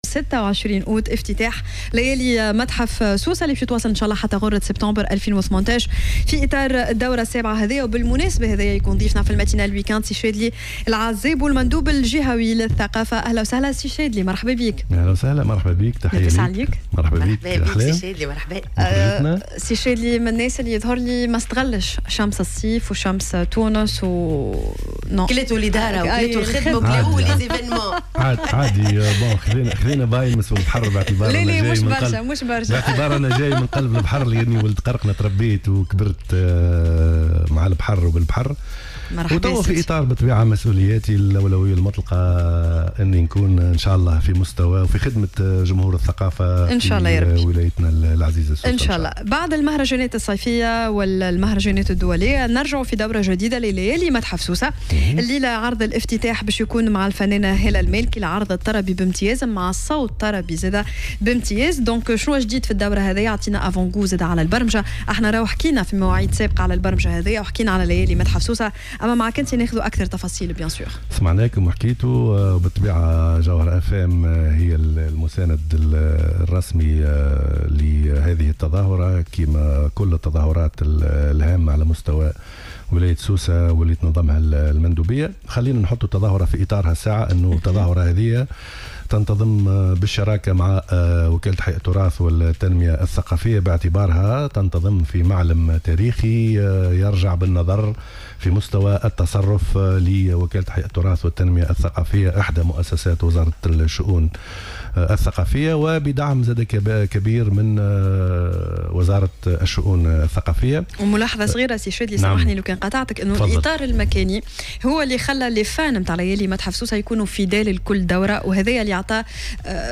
أكد المندوب الجهوي للشؤون الثقافية بسوسة الشاذلي عزابو خلال إستضافته في برنامج " الماتينال " على الجوهرة أف أم أن النسخة الحالية من ليالي متحف سوسة حافظت على مميزاتها التي إتسمت بها منذ سنة 2012 مبرزا أن "الليالي" تتميز بحسن إختيار الفقرات والسهرات.